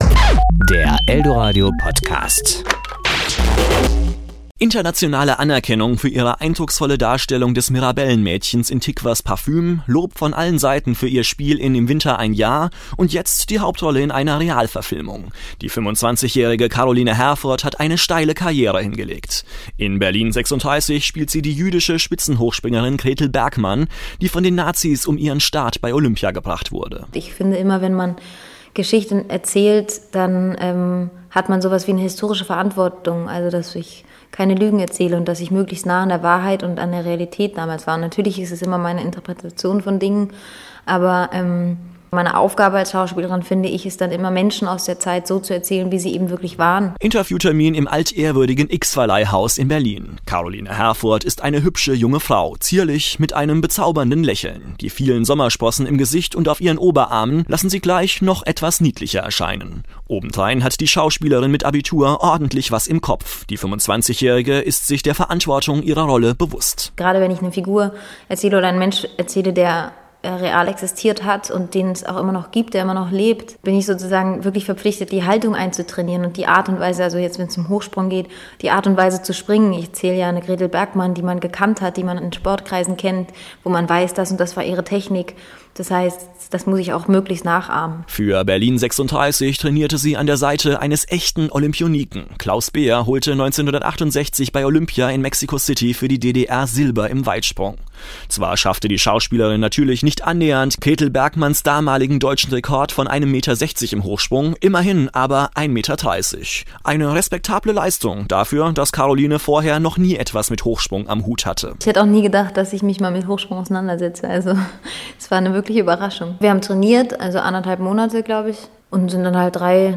Karoline Herfurth im Interview